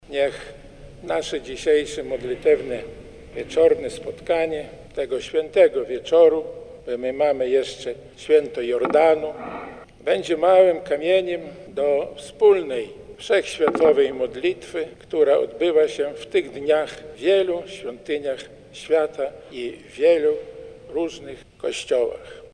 W prawosławnej katedrze świętej Marii Magdaleny na warszawskiej Pradze odbyło się w poniedziałek nabożeństwo ekumeniczne.
Zwierzchnik Polskiego Autokefalicznego Kościoła Prawosławnego arcybiskup Sawa wyraził nadzieję, że modlitewne spotkanie przyczyni się do budowania jedności Chrystusowego Kościoła.